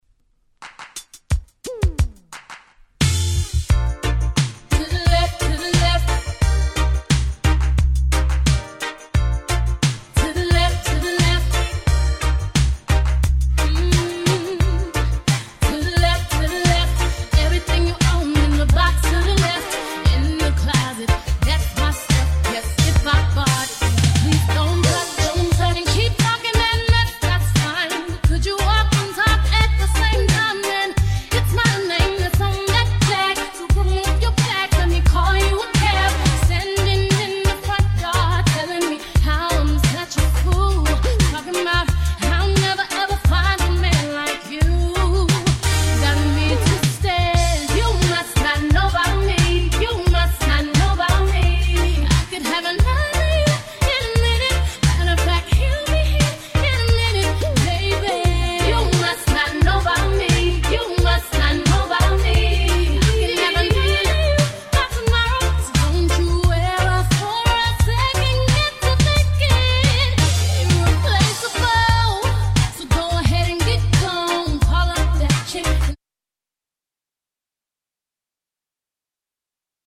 キャッチー系